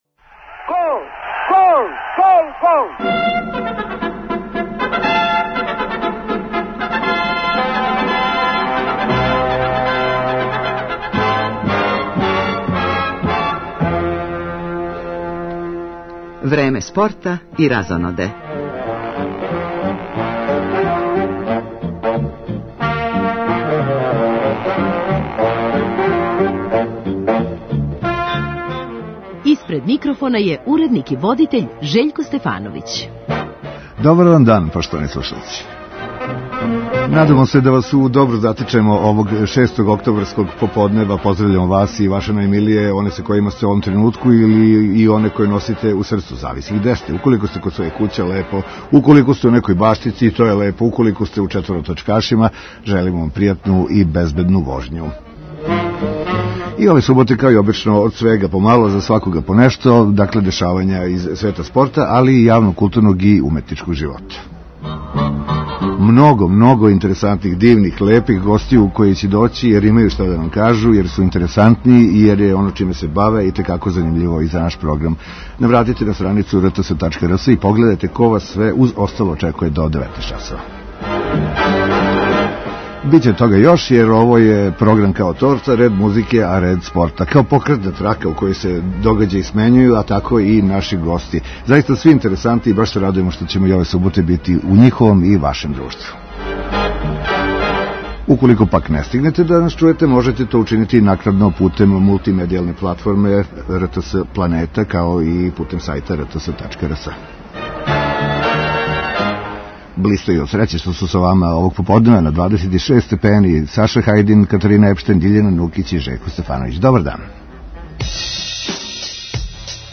Најбоље одбојкашице Србије фуриозно су завршиле прву фазу такмичења на Светском првенству у Јапану, са свих пет победа и без иједног јединог изгубљеног сета. Зоран Терзић и његове изабранице селе се у Нагоју, о ономе што их тамо очекује, као и до краја шампионата, разговарамо с гошћама које се с успехом баве овим спортом.